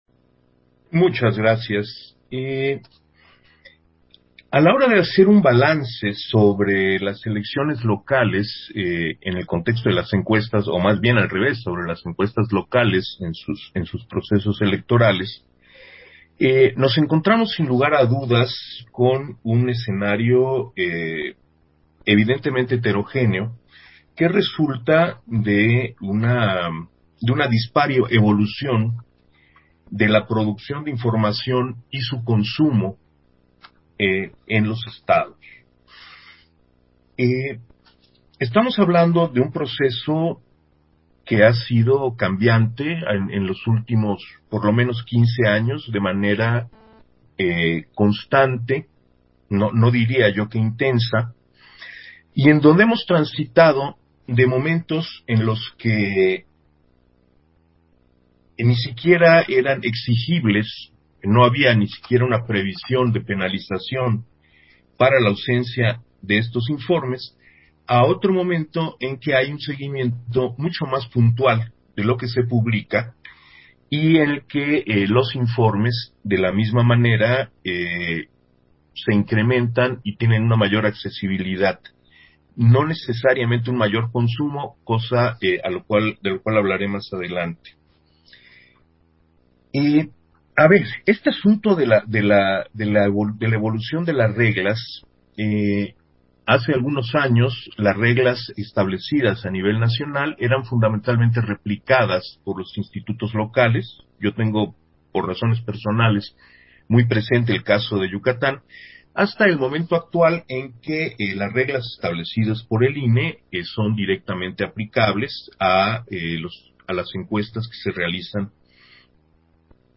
Intervención de Uuc-kib Espadas, en la mesa, Elecciones Locales, en el marco del Foro: Encuestas y Elecciones 2021